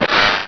carvanha.wav